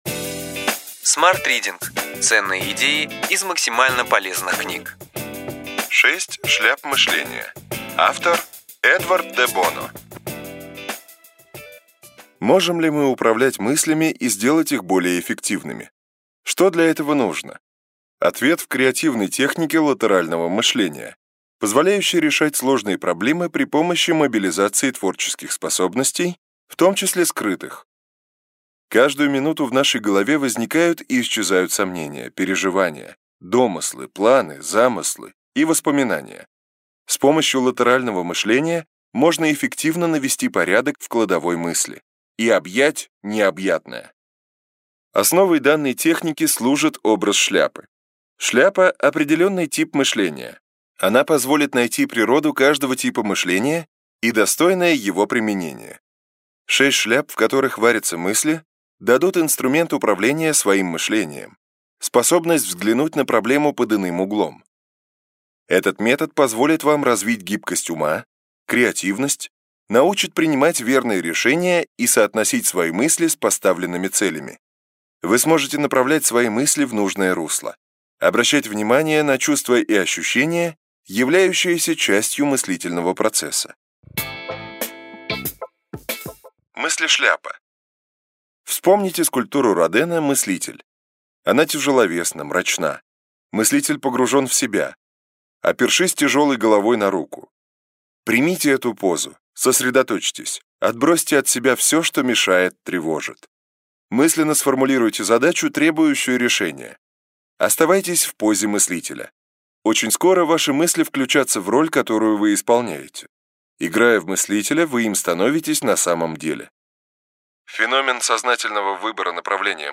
Аудиокнига Ключевые идеи книги: Шесть шляп мышления.